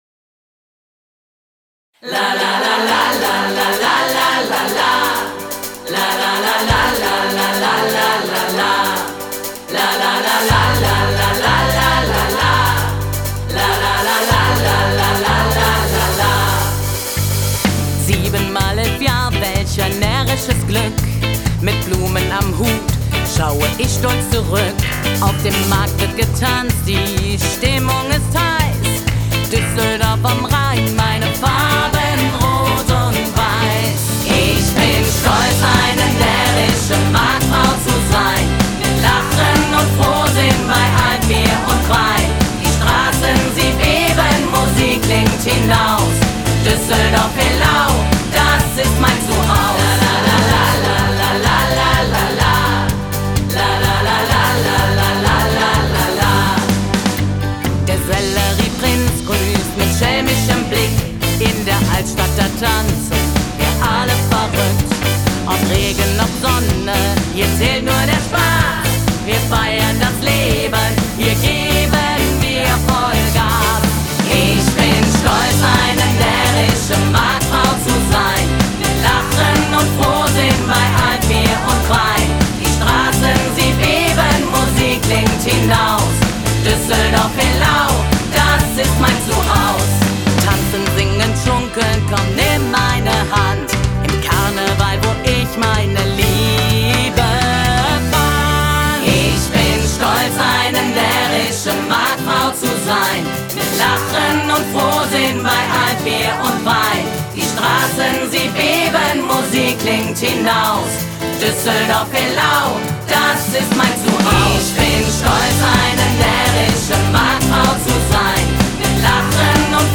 Jubiläumslied